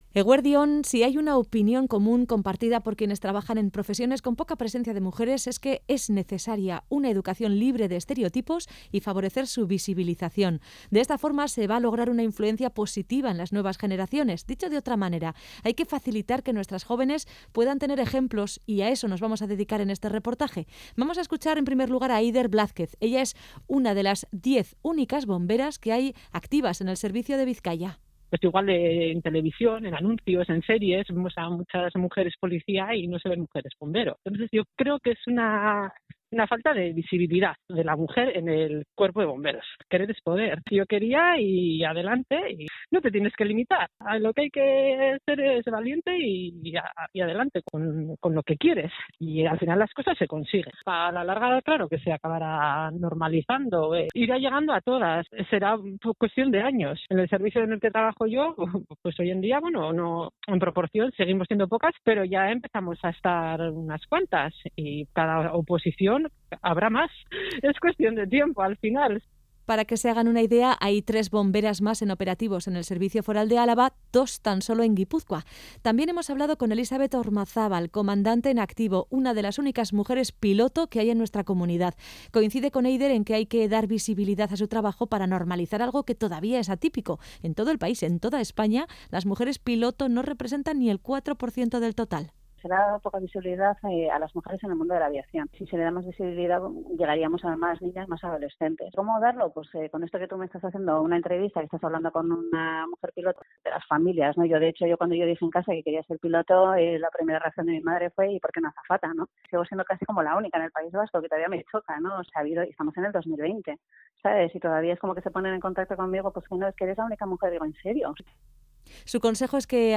Reportaje sobre la presencia de la mujer en distintas profesiones que, habitualmente, no cuentan con demasiadas mujeres
Reportaje especial aparecido en Onda Cero Bilbao sobre la presencia femenina en distintas profesiones.